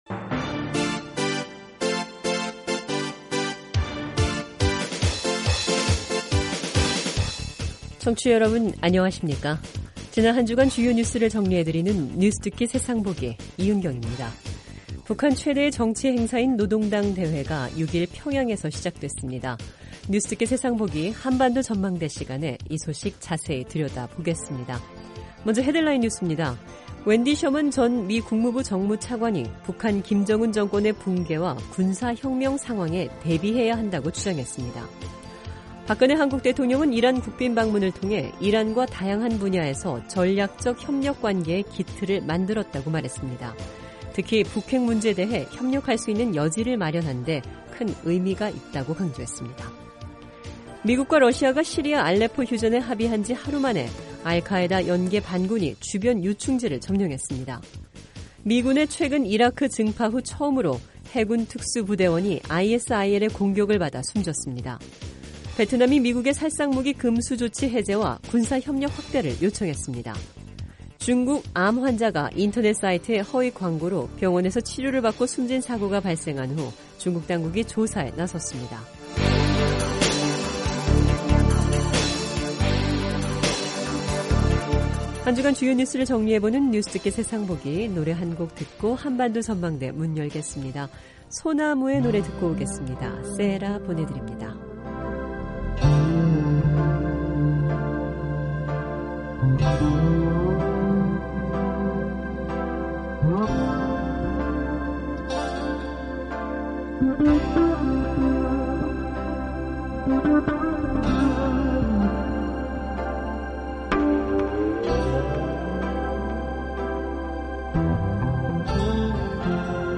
지난 한주간 주요 뉴스를 정리해 드리는 뉴스듣기 세상보기 입니다. 북한 최대의 정치 행사인 노동당 대회가 6일 평양에서 시작됐습니다. 미국과 러시아가 시리아 알레포 휴전에 합의한 지 하루만에, 알카에다 연계 반군이 주변 요충지를 점령했습니다.